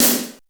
BIG SD.wav